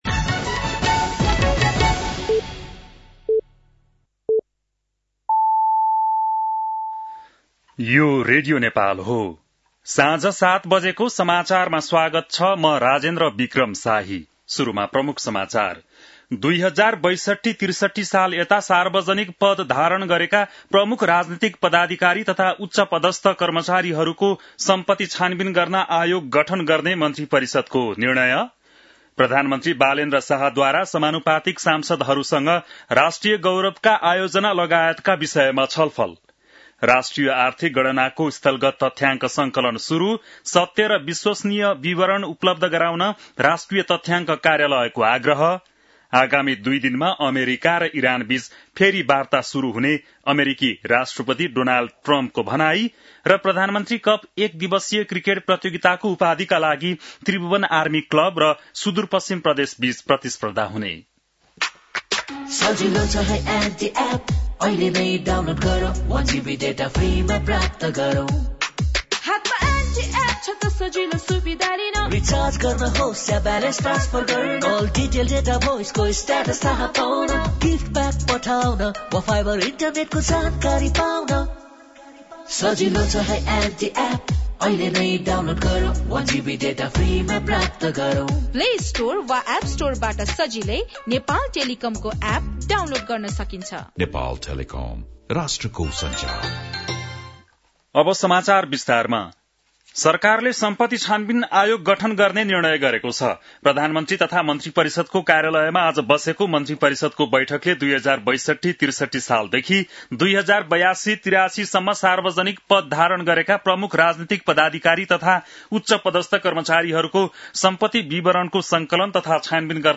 बेलुकी ७ बजेको नेपाली समाचार : २ वैशाख , २०८३
7-pm-nepali-news-1-02.mp3